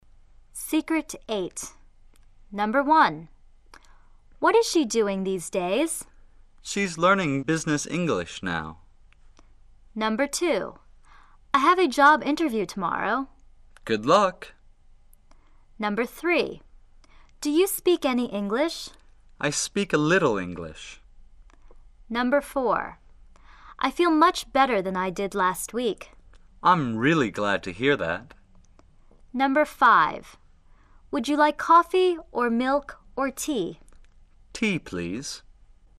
长元音一般是通过“口腔”发出的，发声处“靠前”；而短元音则是用“喉咙”发出的，发音处尽量“靠后”。